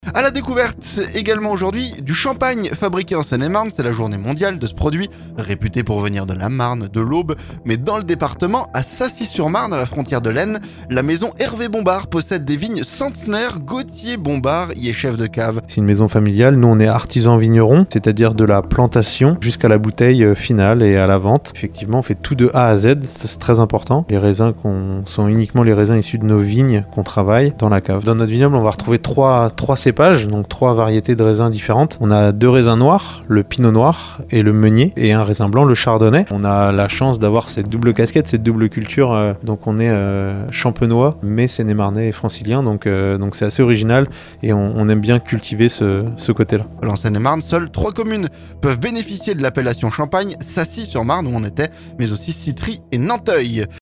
CHAMPAGNE - Reportage chez un producteur de Seine-et-Marne